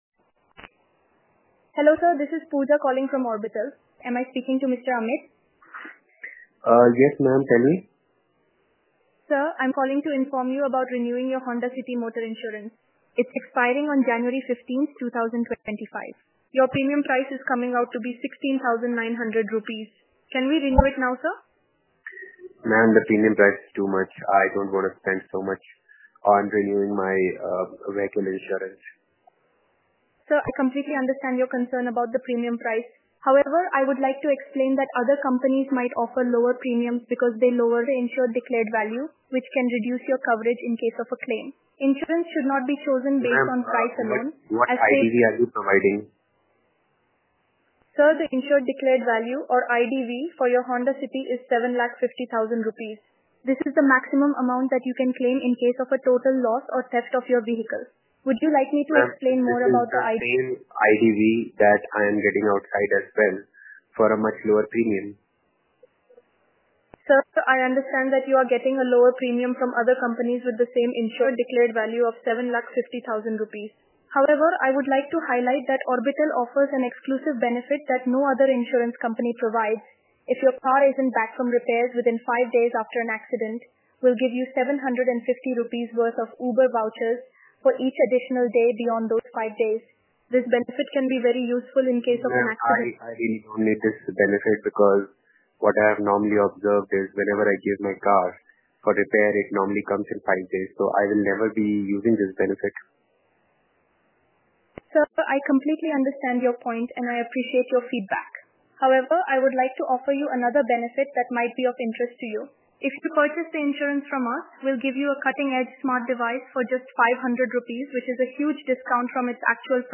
Reshaping the world of customer interaction with AI Voice Agents
Our agents sound indistinguishably human - it's hard to discern them as AI!